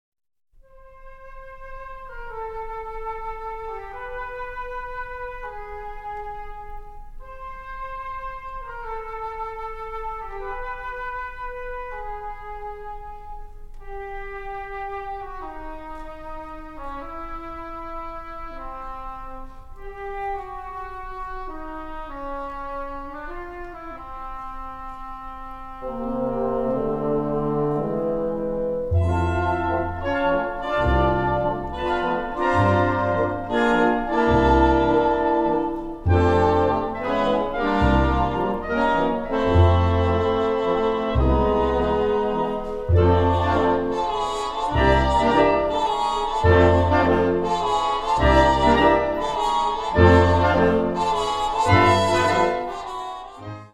Bezetting Ha (harmonieorkest)
heldere, ietwat 'jazzy' suite